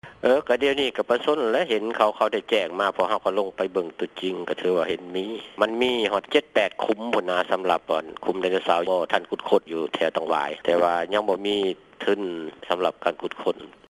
ທ່ານ ສີນວນ ສີຫາຣາຊ, ຫົວໜ້າ ຫ້ອງການ ວິທະຍາສາດ, ເທັກໂນໂລຈີ ແລະ ສິ່ງແວດລ້ອມ ແຂວງສວັນນະເຂດ ກ່າວວ່າ: